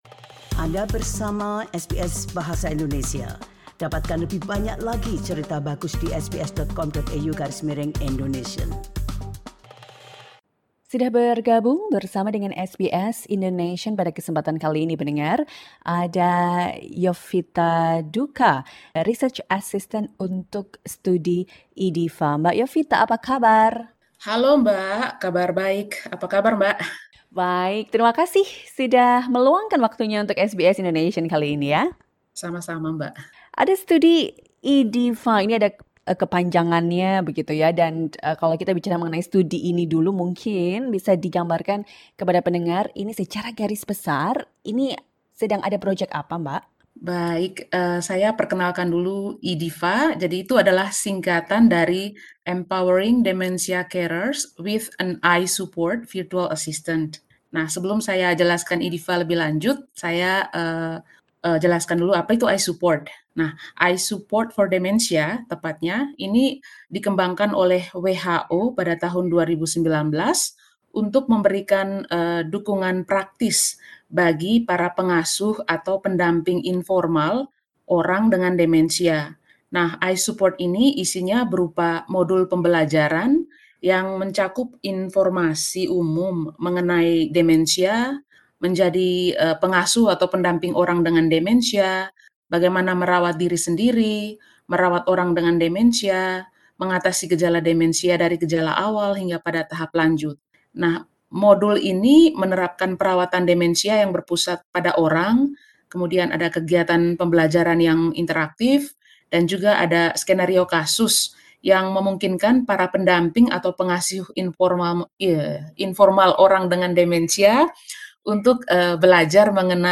Listen to the full interview on the SBS Indonesian podcast, or by clicking on the image above.